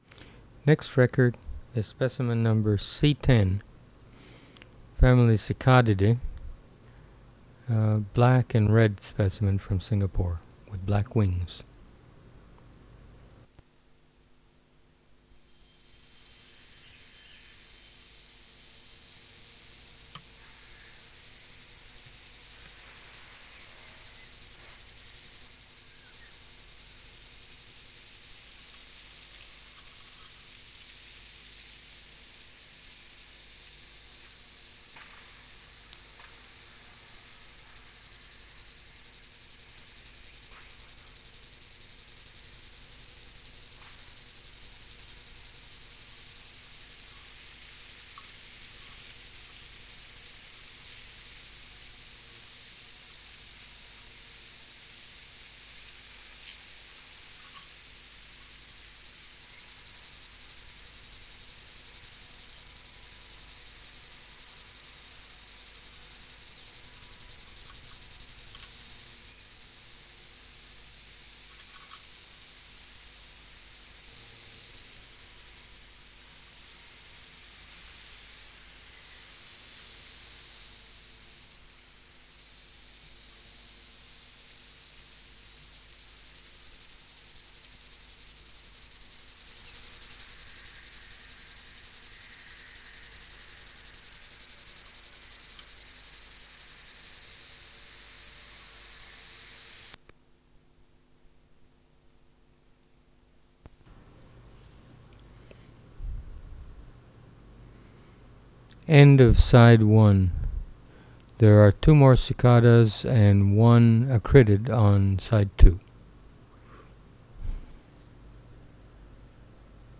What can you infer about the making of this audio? Recording Location: Asia: Singapore